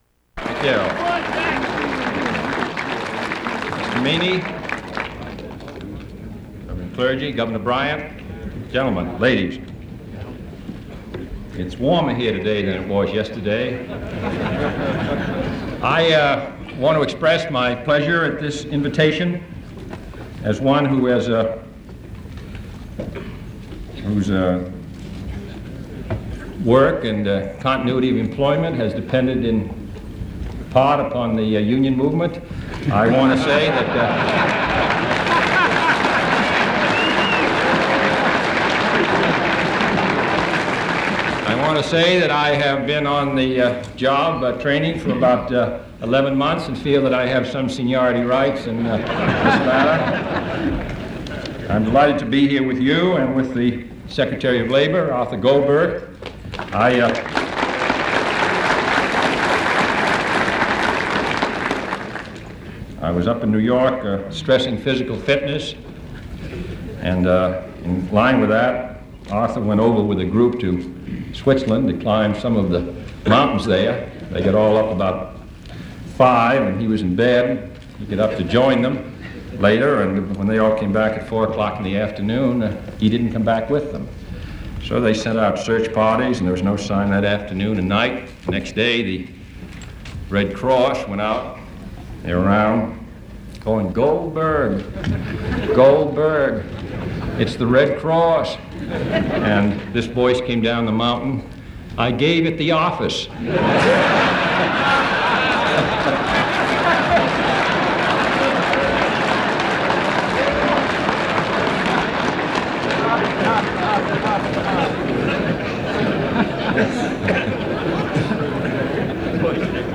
U.S. President John F. Kennedy address an AFL-CIO convention